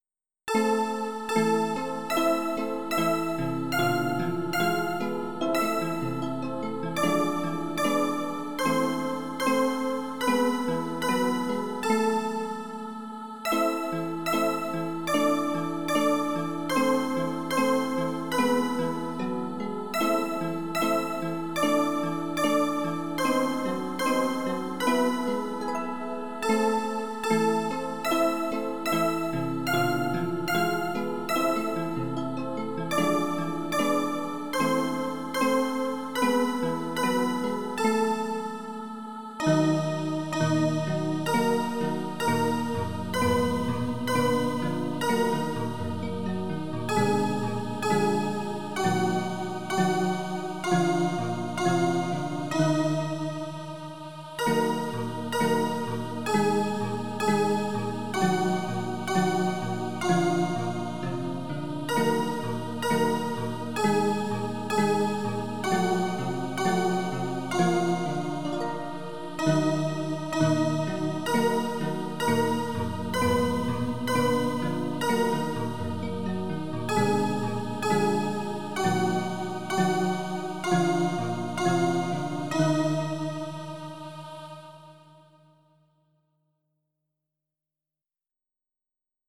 folk song France